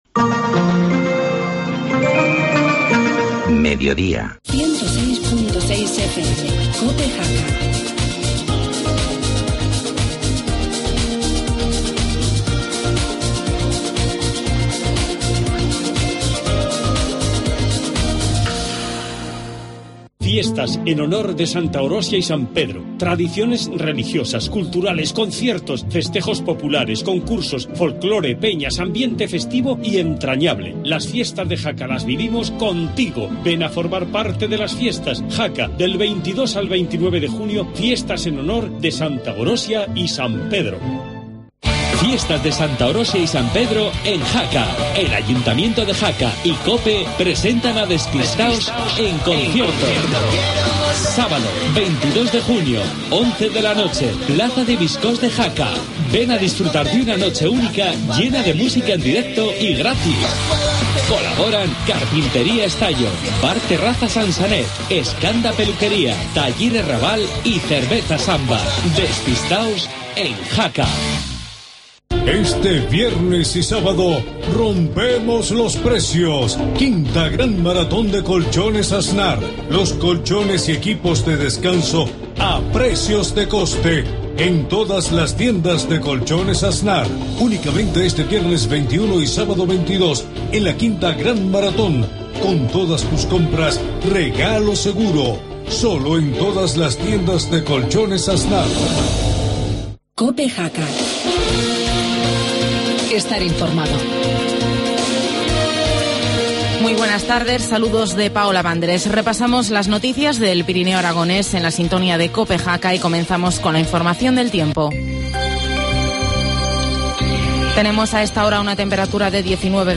Informativo mediodía, jueves 20 de junio